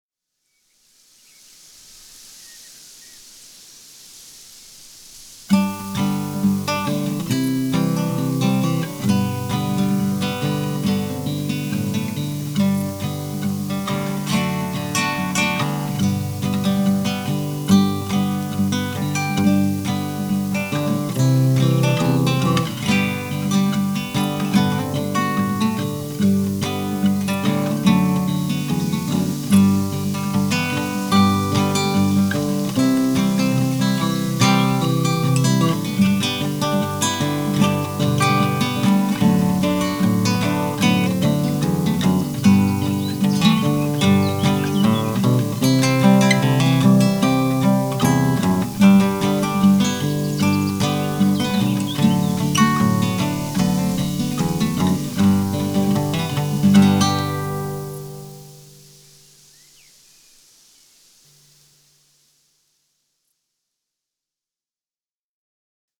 Auf dieser Seite finden Sie einige Hörbeispiele unterschiedlicher Gitarren-Stilrichtungen
- American Fingerstyle, Eigenkomposition -